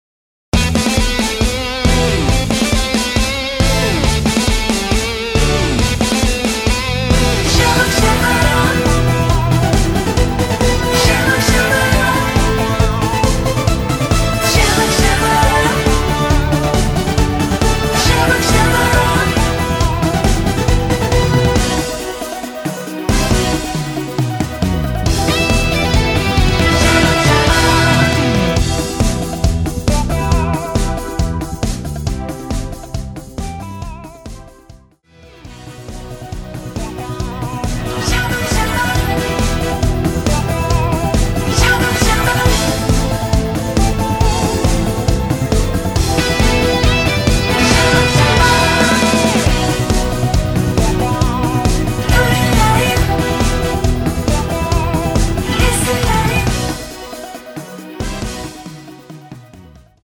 (-2)내린 코러스 포함된 MR 입니다.
Em
앞부분30초, 뒷부분30초씩 편집해서 올려 드리고 있습니다.
중간에 음이 끈어지고 다시 나오는 이유는